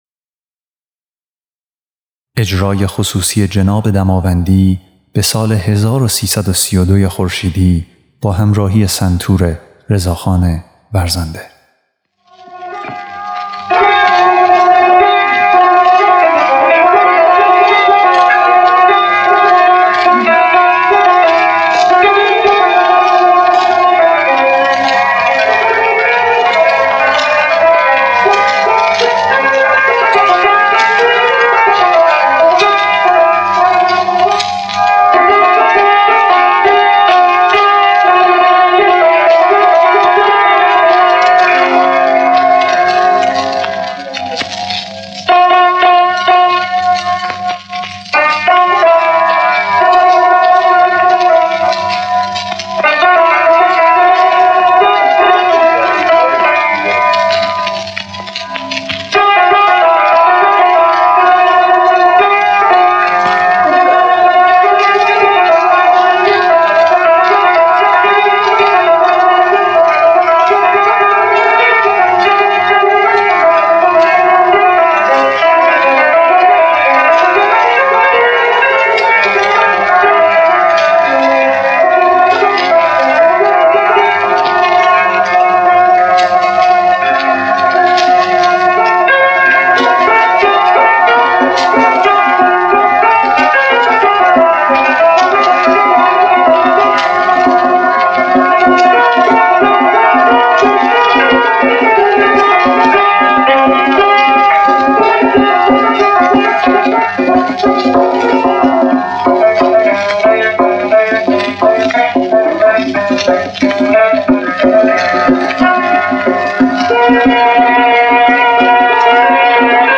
اجرای خصوصی جناب دماوندی ضبط 1332 - جناب دماوندی
آخرين يادگار از آواز جناب دماوندي در سال 1332 خورشيدي و در يک ميهماني خصوصي با حضور جمعي از موسيقيدانان وقت، مانند سيد حسين طاهرزاده، ابوالحسن‌صبا، حسين